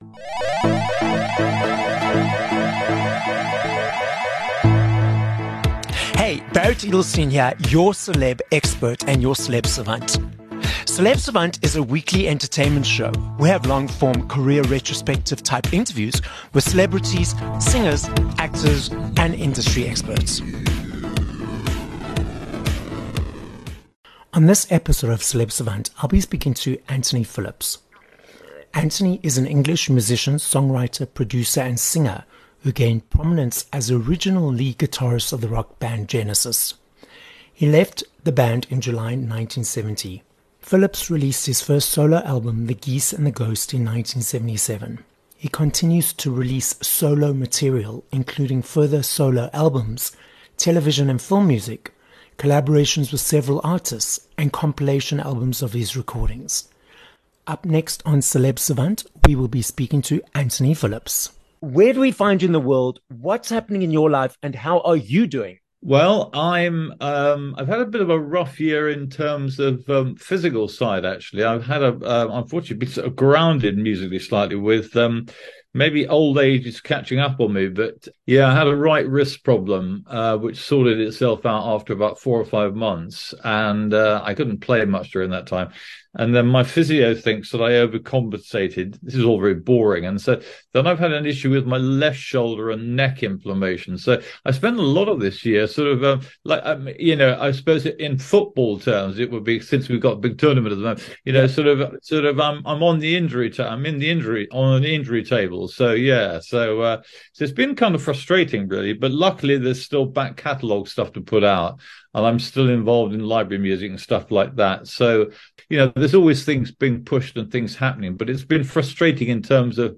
26 Jan Interview with Anthony Phillips